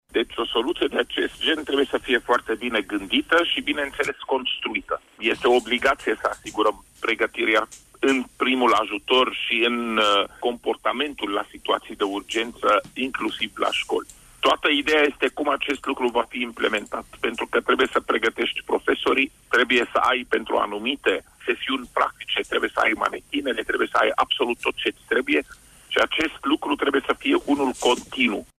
Șeful DSU, Raed Arafat a confirmat la Radio România necesitatea introducerii unor astfel de cursuri în formă continuă: